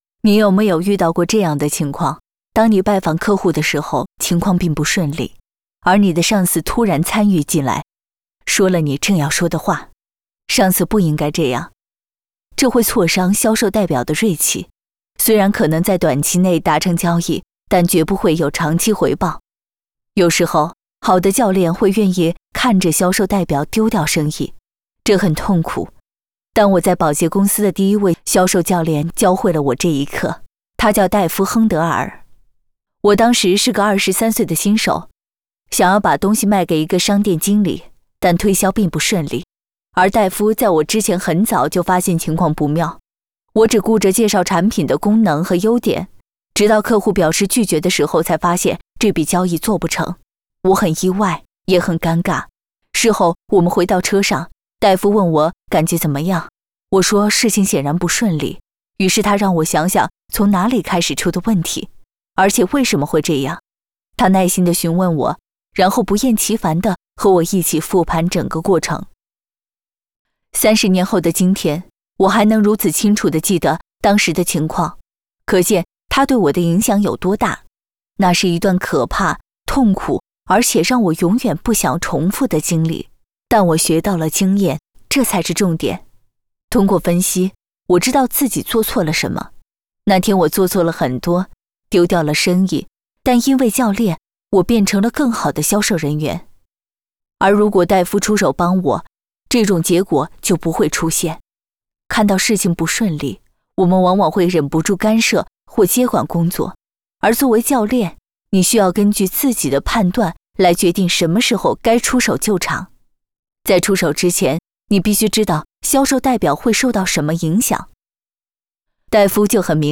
Chinese_Female_018VoiceArtist_8Hours_High_Quality_Voice_Dataset